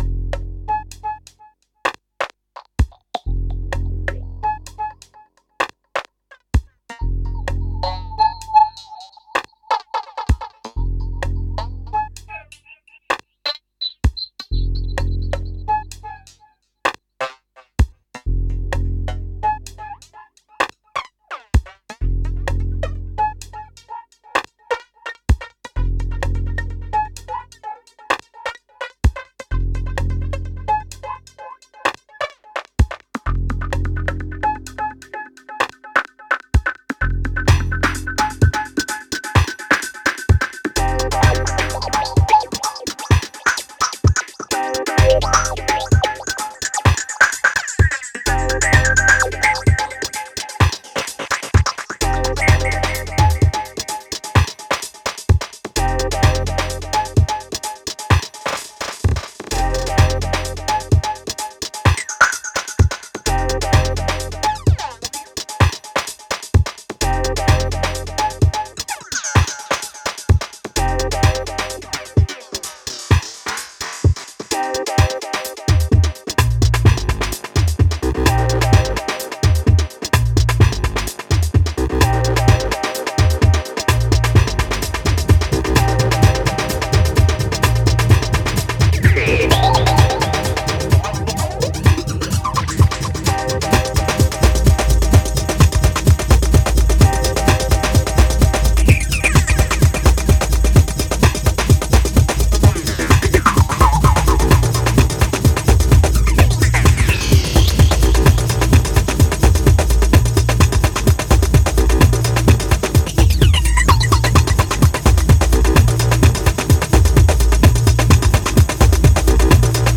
Full-on raw rehearsal takes of Acid Ambient tracks.